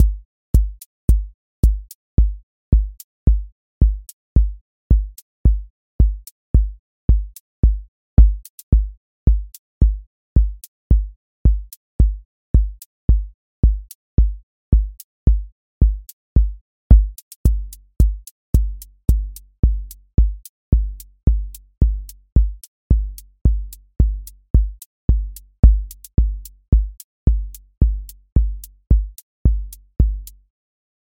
Four Floor Drive QA Listening Test house Template: four_on_floor April 17, 2026 ← Back to all listening tests Audio Four Floor Drive
four on floor
voice_kick_808 voice_hat_rimshot voice_sub_pulse